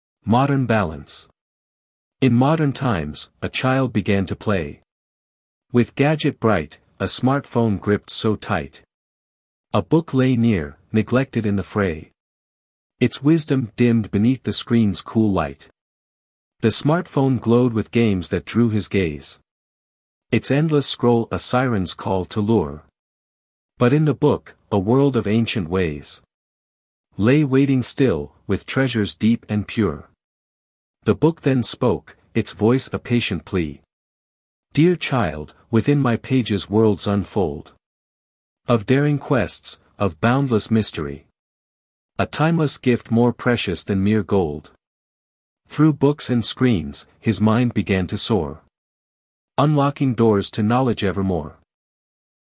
A sonnet is a 14-line poem with a specific rhythm and rhyme pattern.